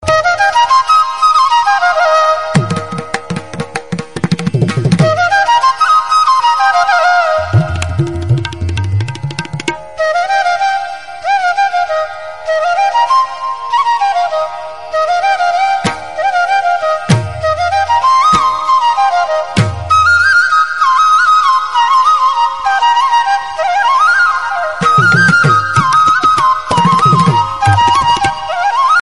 Devotional